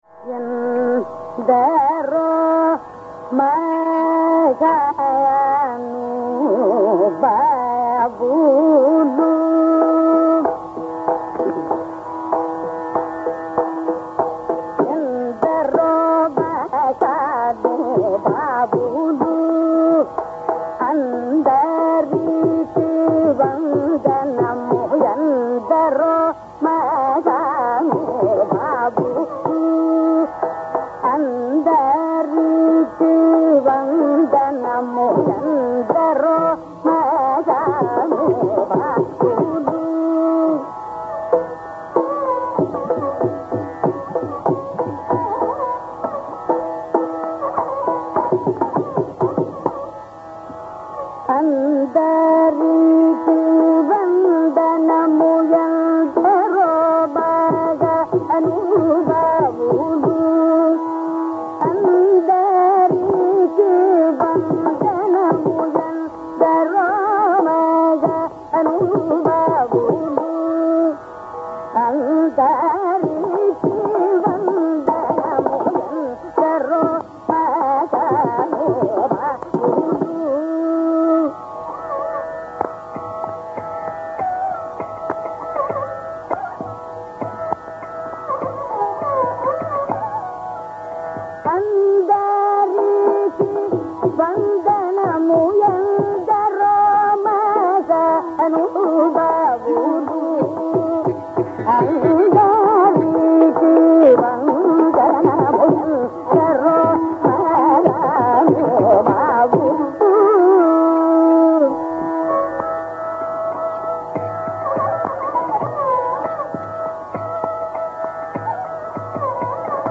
Our first example is a pallavi sung by
D. K. Pattammal, at the Tyagaraja Aradhana in Tiruvaiyaru.
It is worth noting that Pattammal, who is often described as a musician with great fidelity to tradition (whatever that should mean), sings it in the raga Kambodhi and not in Sriraga.